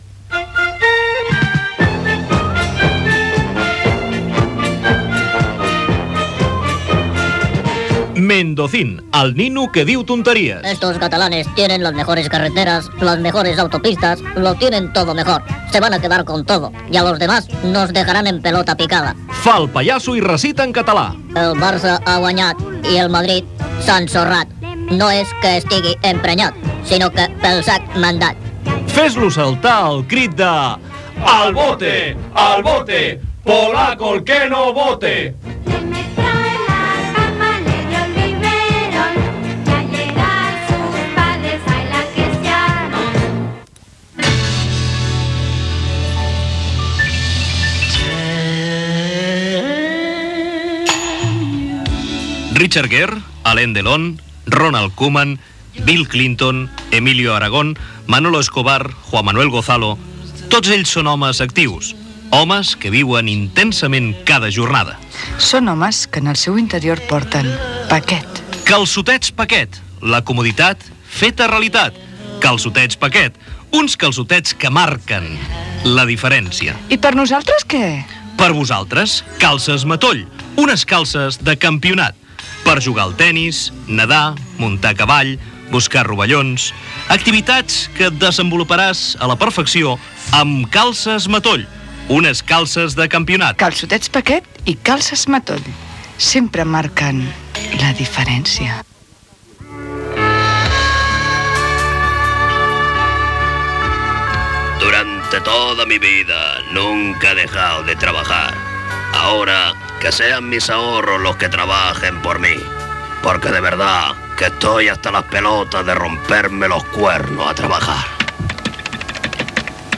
Publicitat :"Mendozin", " calçotets Paquet i calces Matoll", "Caixa d'Estalvis del Món Sense Pietat".
Entreteniment